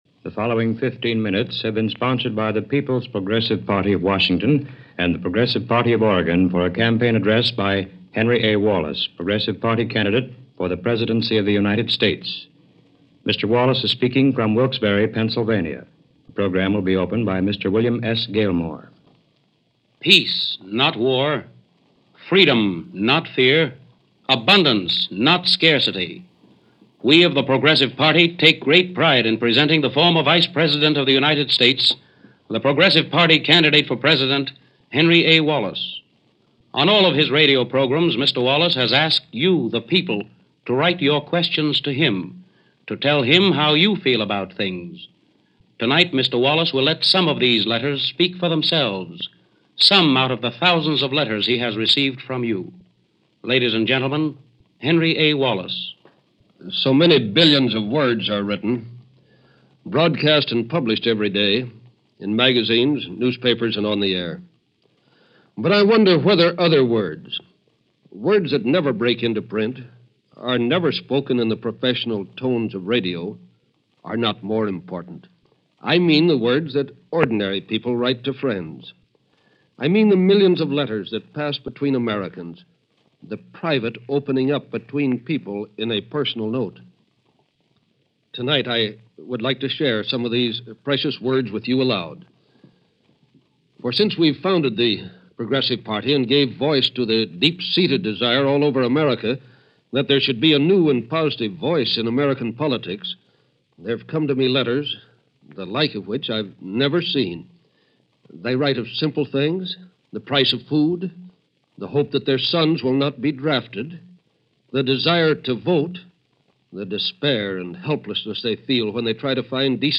On this radio talk, given October 20, 1948 Wallace reads letters from average Americans , asking questions that sound surprisingly familiar in 2015. Questions about jobs, poverty, the vanishing middle-class, the race issue our economy and the imbalance of power in Washington.